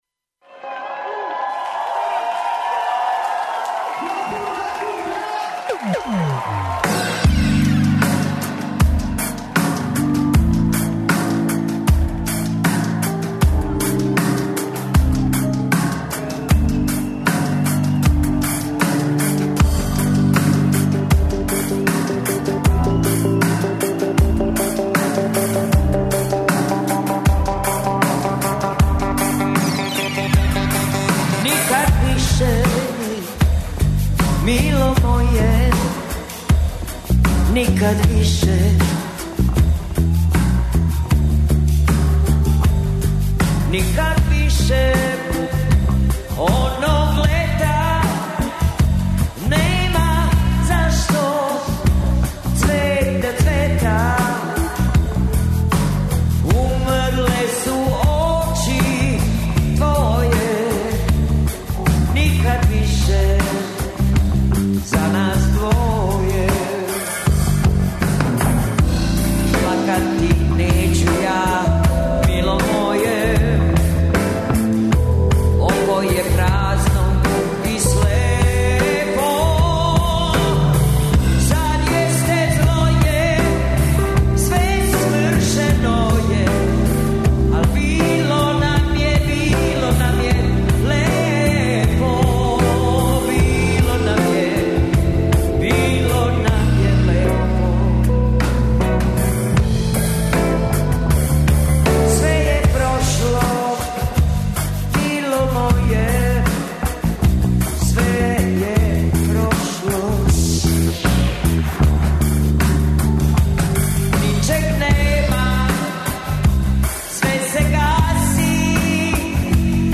Емисију емитујемо уживо из Сокобање, представићемо вам културни живот овога града.